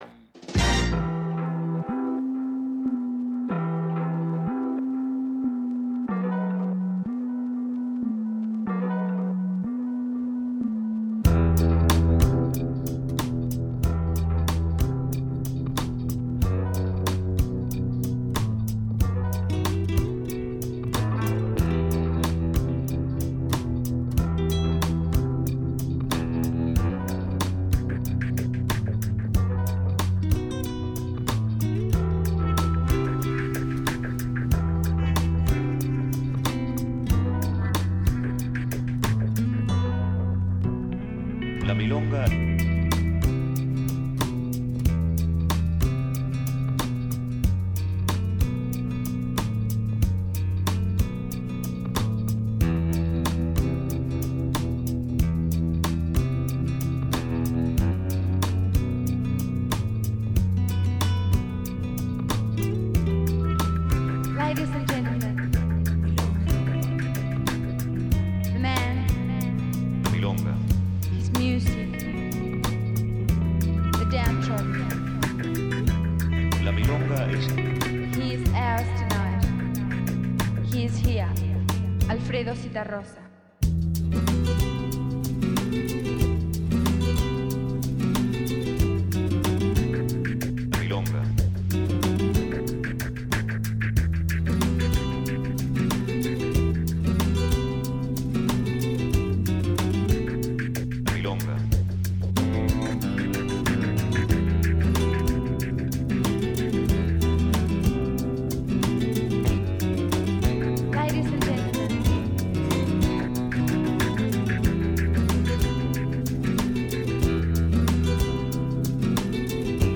την ηλεκτρονική αναβίωση του τάνγκο